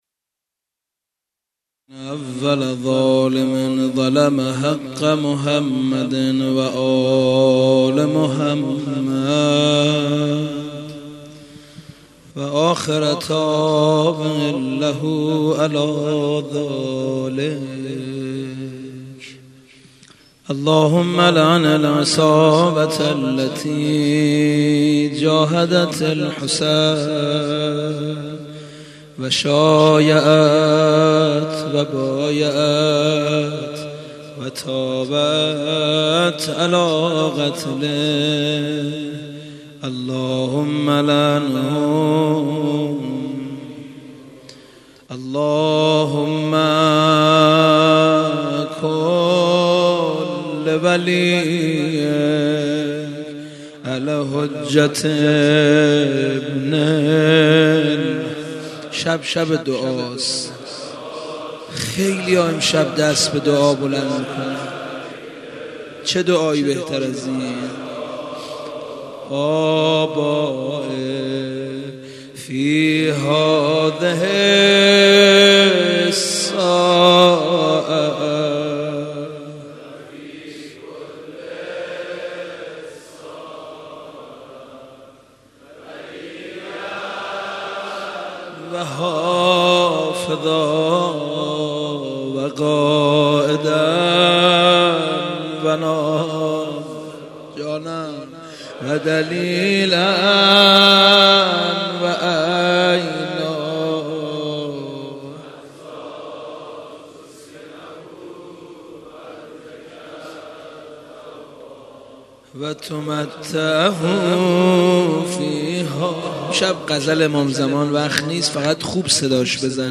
زمینه، روضه، مناجات